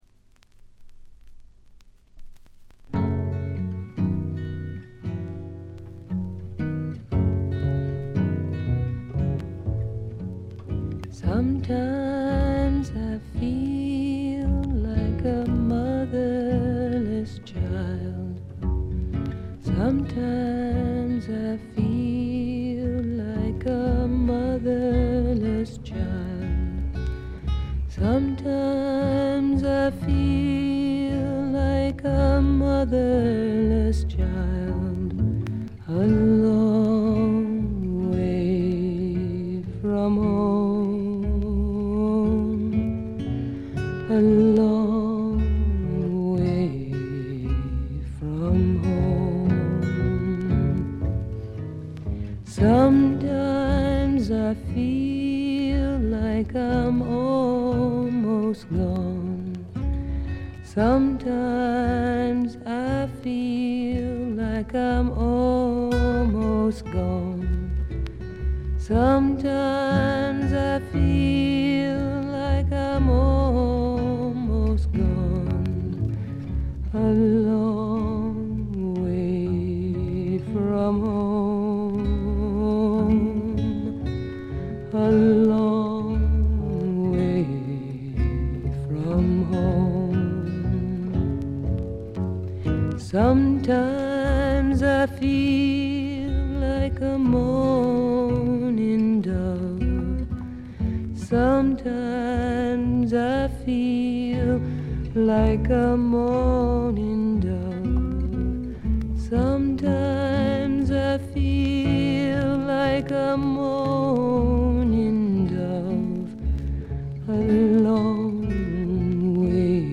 ただしA面ラスト曲終盤ノイズが大きくなります。
試聴曲は現品からの取り込み音源です。